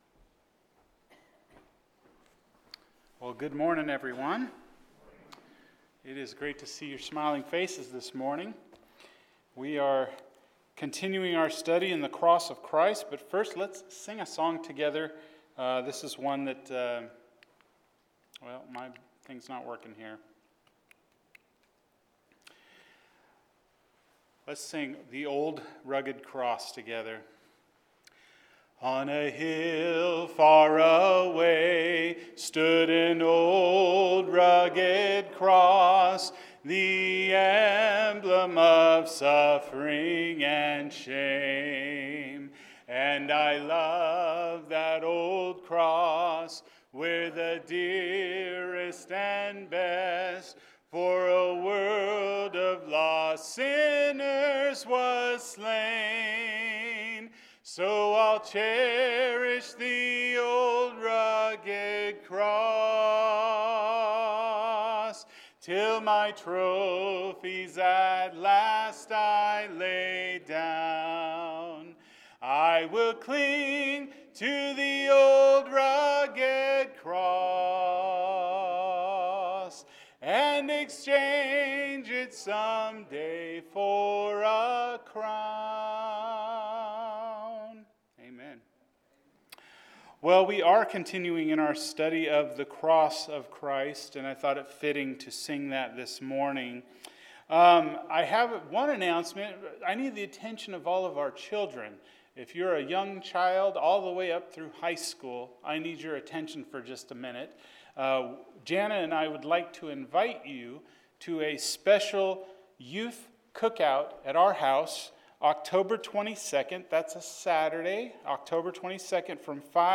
The Cross is Powerful – Matthew 27:45-54 – Sermon